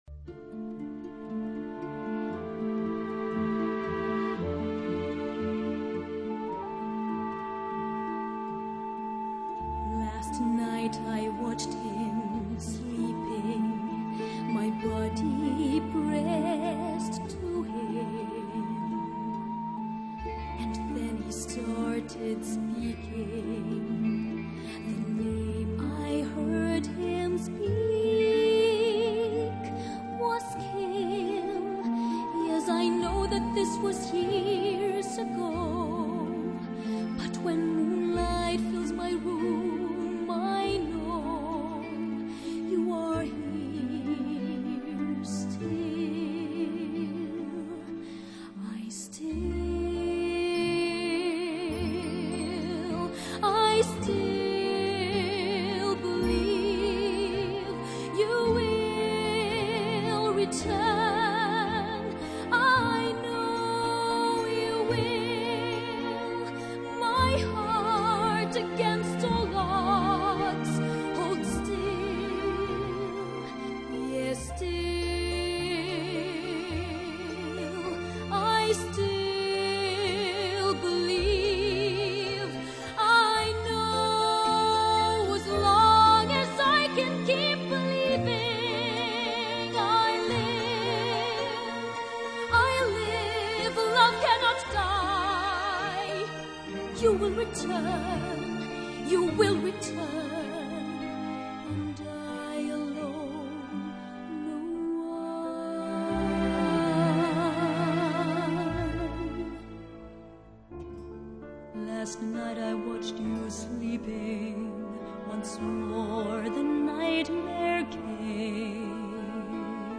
這首歌是本劇兩位女角 Kim 與 Ellen 一起唱的曲子